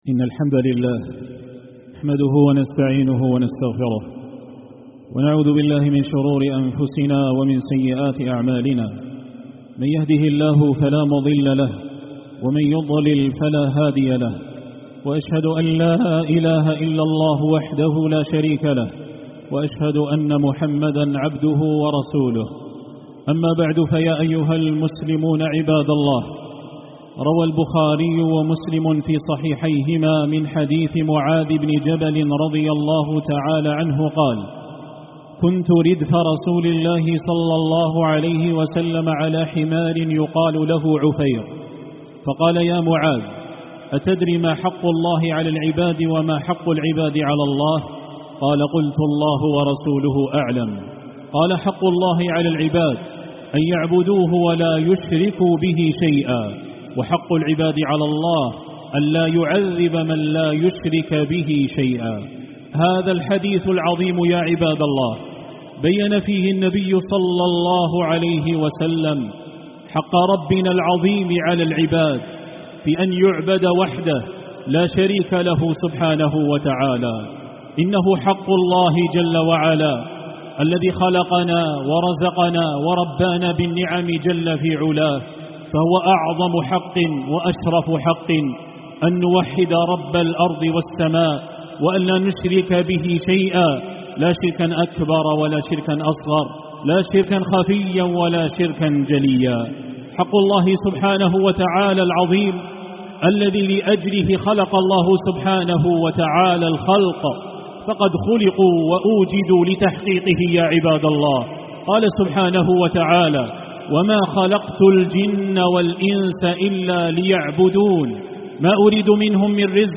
10 جانفي 2025 م 4.7M 00:10:17 عقيدة 102 7 باقي خطب الشيخ كل الخطب سماع الخطبة تحميل الخطبة شارك